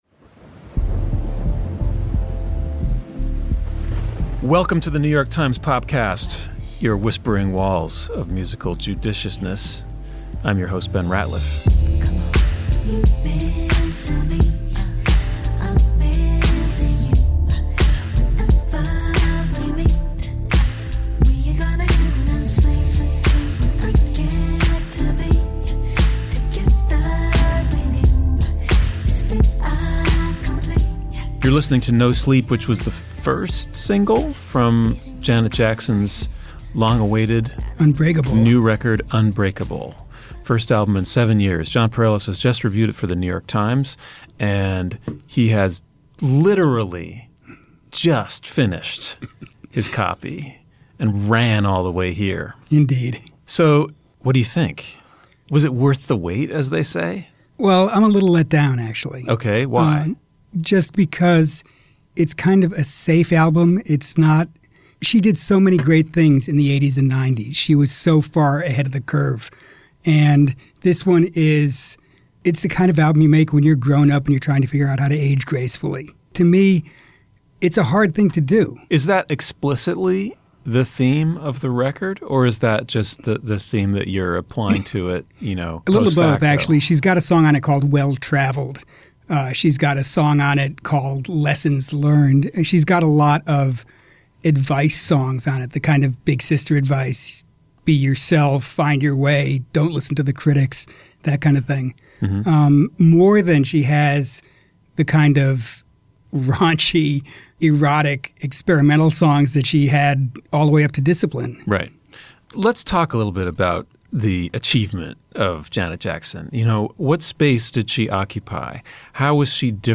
Times music critics discuss Ms. Jackson’s new album and tour.